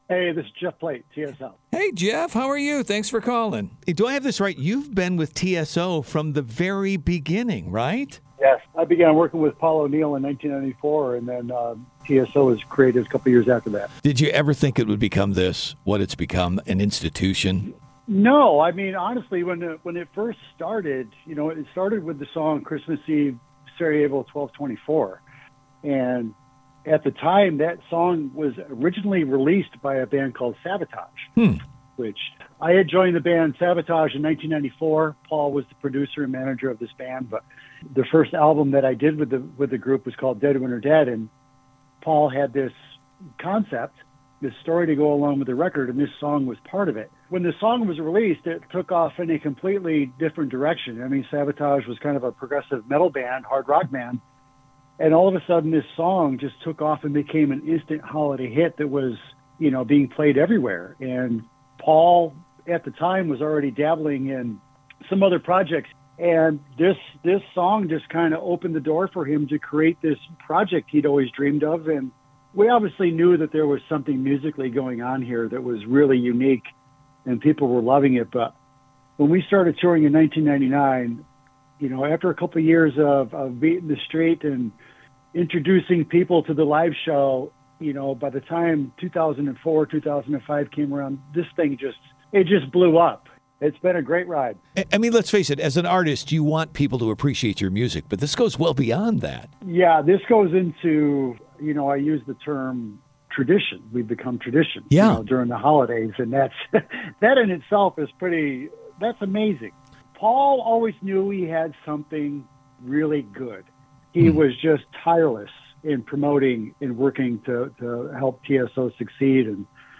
JEFF PLATE Interview https